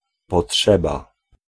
Ääntäminen
Synonyymit attente recommandataire Ääntäminen France: IPA: [bə.zwɛ̃] Tuntematon aksentti: IPA: [bœzwæ̃] IPA: /bzwɛ̃/ Haettu sana löytyi näillä lähdekielillä: ranska Käännös Ääninäyte 1. potrzeba {f} Suku: m .